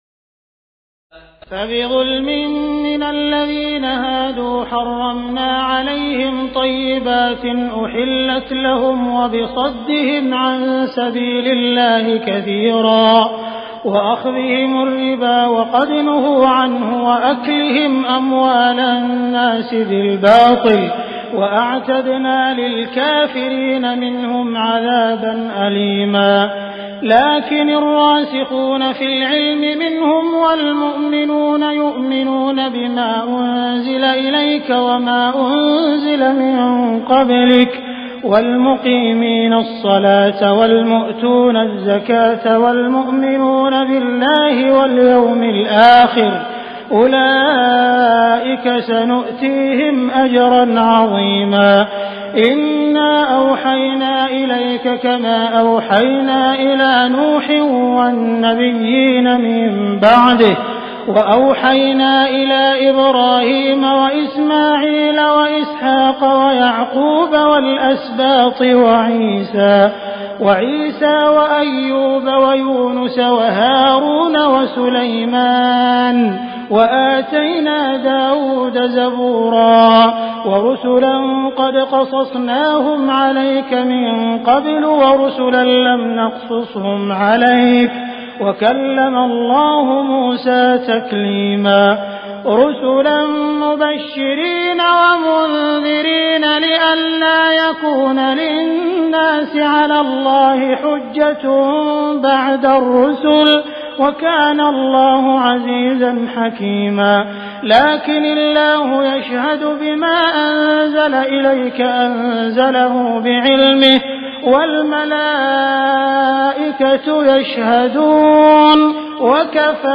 تراويح الليلة السادسة رمضان 1418هـ من سورتي النساء (160-176) و المائدة (1-40) Taraweeh 6 st night Ramadan 1418H from Surah An-Nisaa and AlMa'idah > تراويح الحرم المكي عام 1418 🕋 > التراويح - تلاوات الحرمين